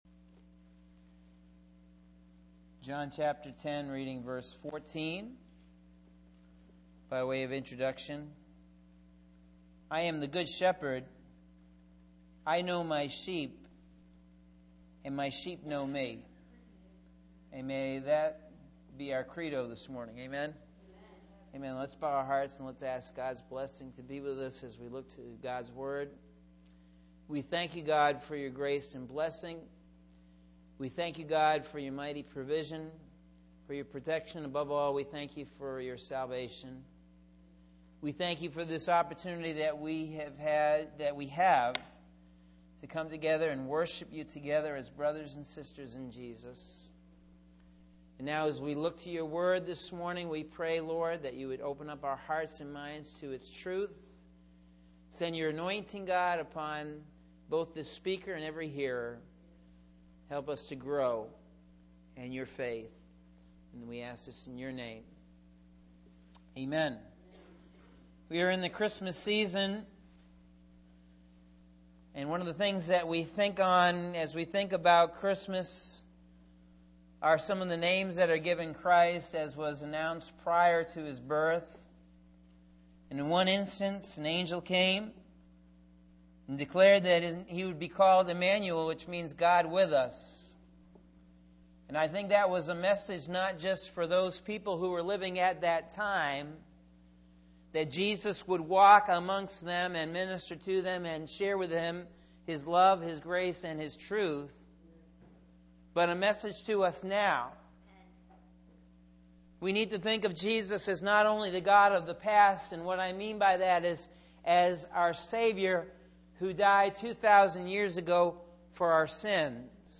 Sunday December 6th AM Sermon – Norwich Assembly of God